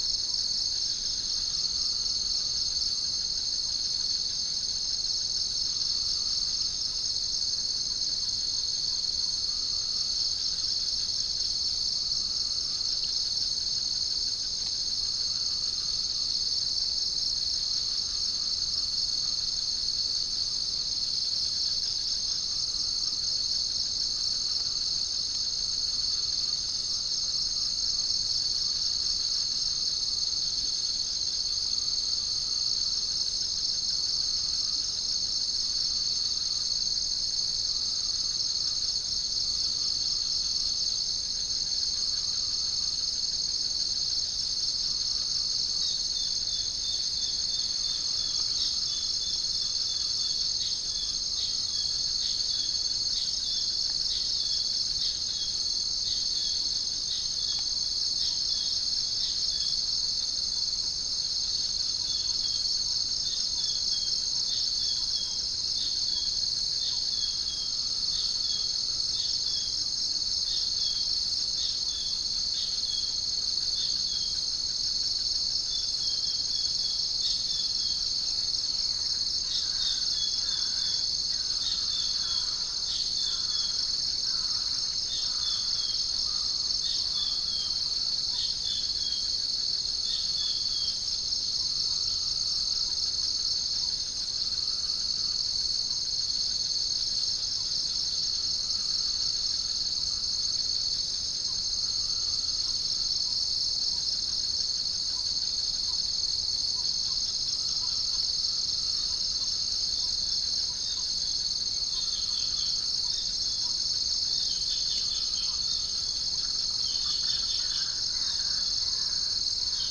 Amaurornis phoenicurus
Geopelia striata
Centropus bengalensis
Orthotomus sericeus
Todiramphus chloris